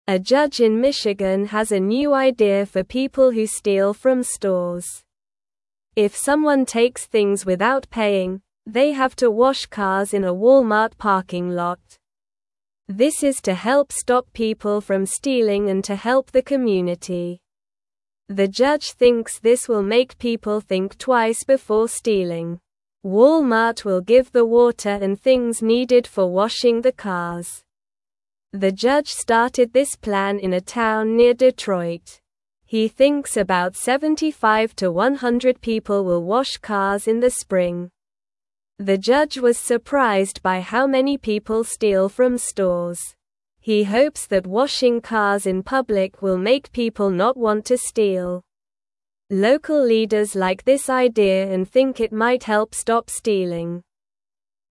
Slow
English-Newsroom-Beginner-SLOW-Reading-Judges-New-Plan-to-Stop-Store-Stealing.mp3